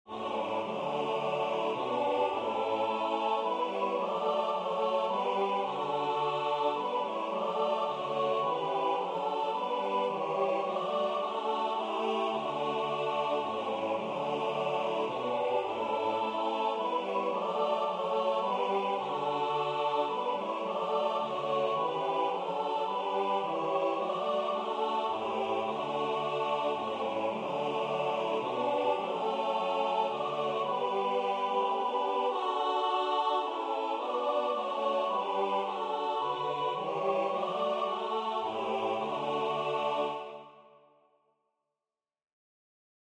English carol